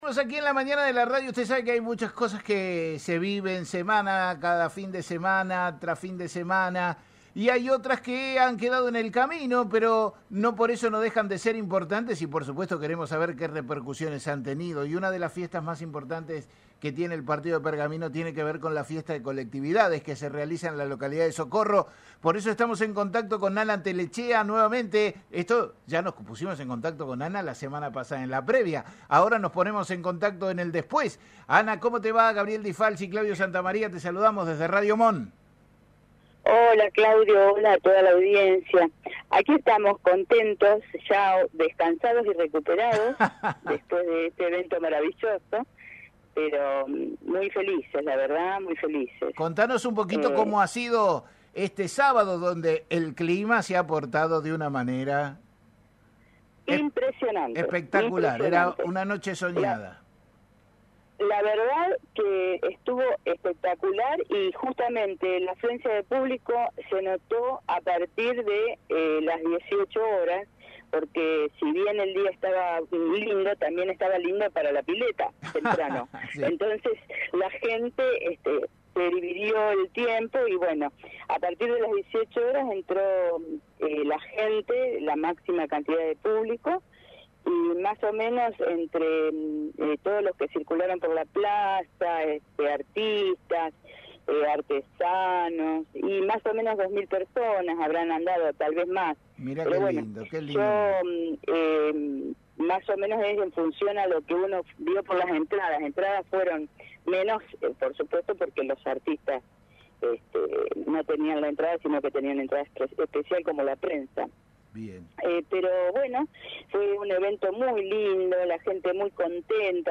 dialogó con «La Mañana de la Radio»